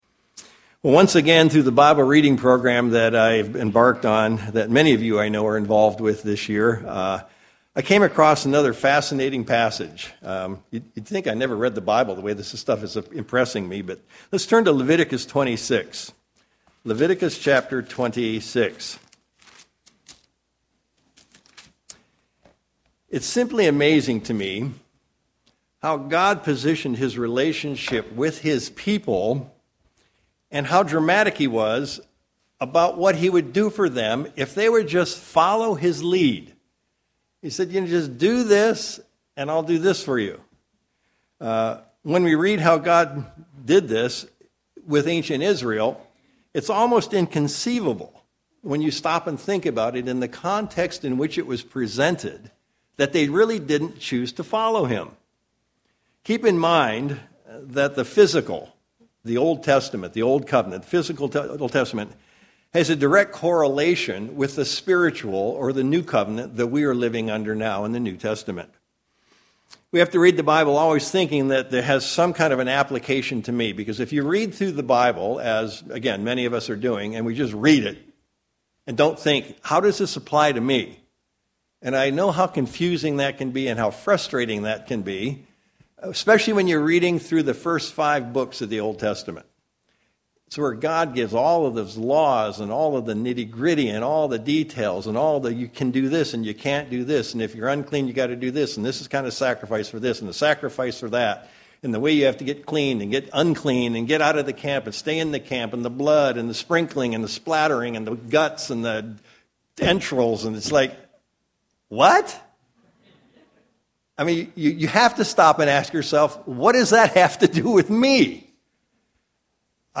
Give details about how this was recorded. Given in Sacramento, CA